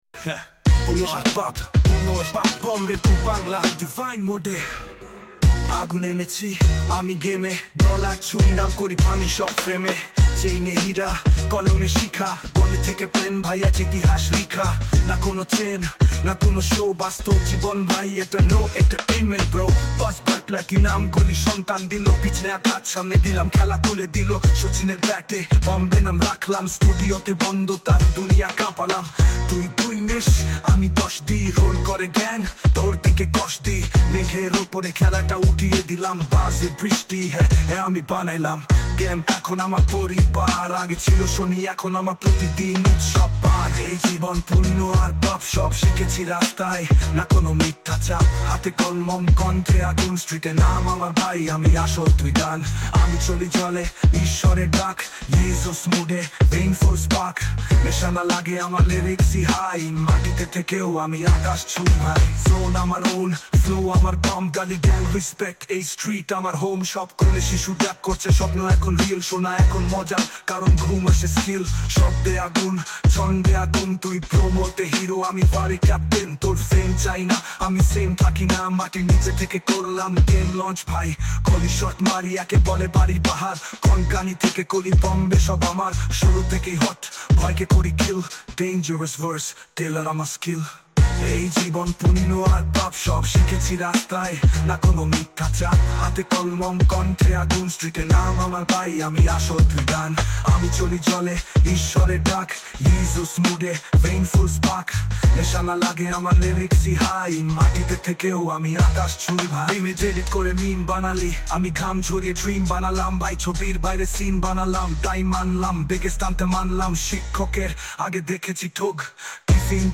Vocal & Beat by AI